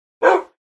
Minecraft Dog Bark